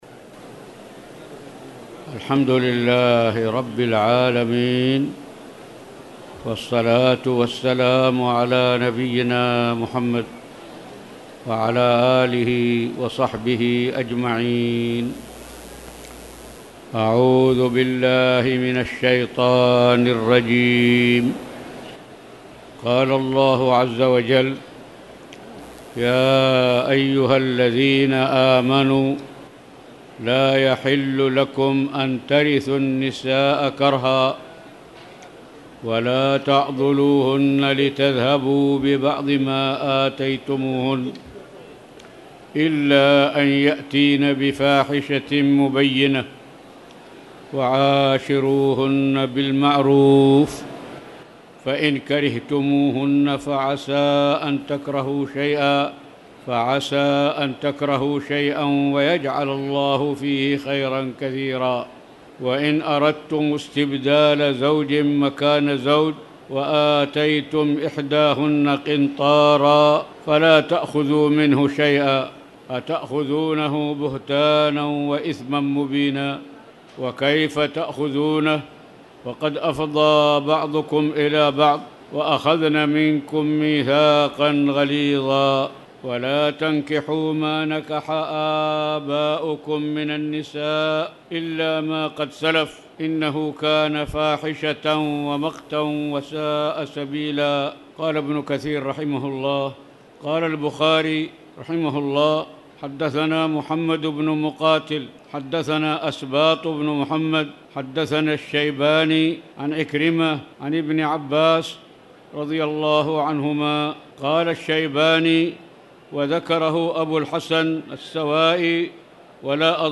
تاريخ النشر ٧ رمضان ١٤٣٨ هـ المكان: المسجد الحرام الشيخ